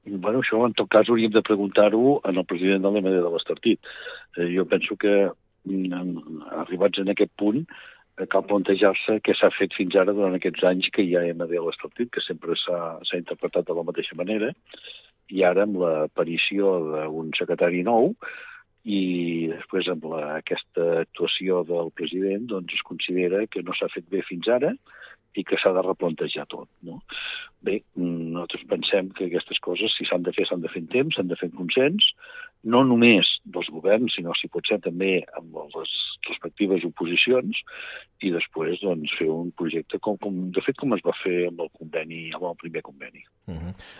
Per entendre millor què està passant i quines mesures es prendran avui ens ha visitat al Supermatí l’alcalde de Torroella de Montgrí, Jordi Colomí.